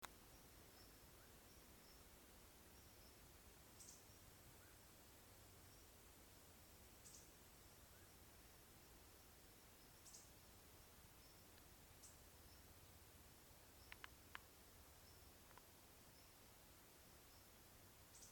Hormiguero Escamoso (Myrmoderus squamosus)
Localidad o área protegida: Bombinhas--trilha do Morro das Antenas
Condición: Silvestre
Certeza: Fotografiada, Vocalización Grabada